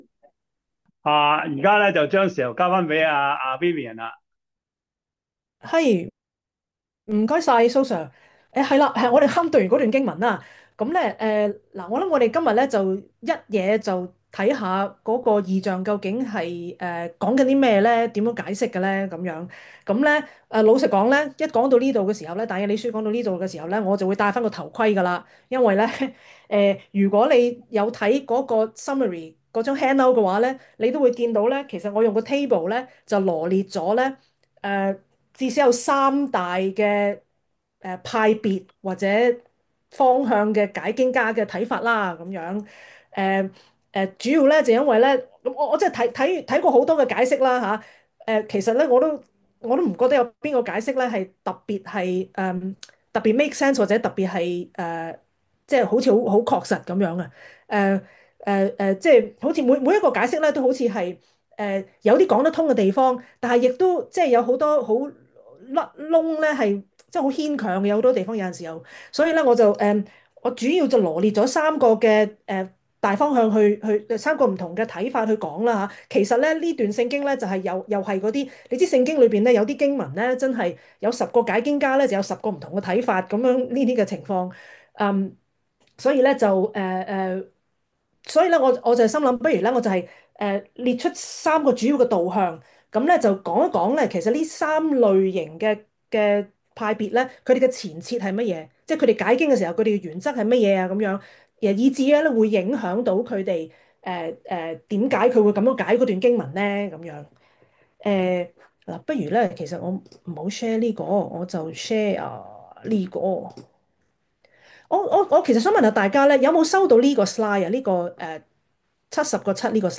中文主日學